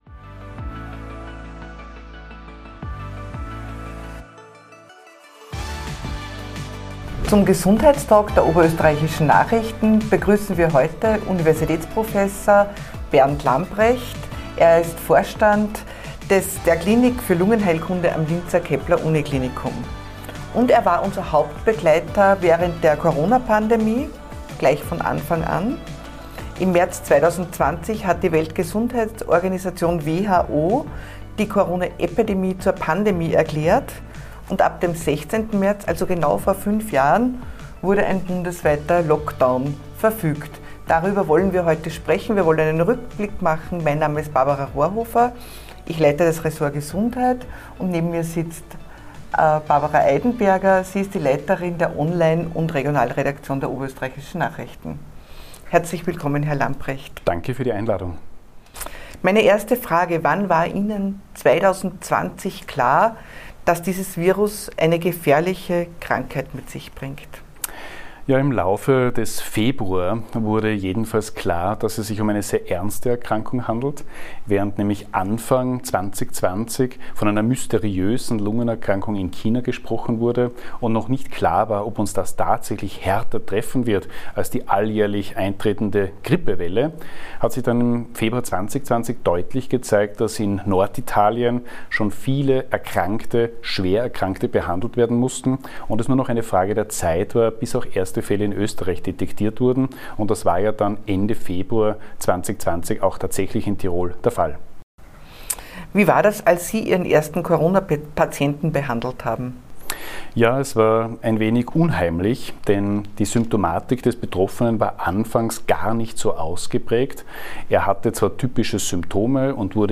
ein Interview und ein Rückblick ...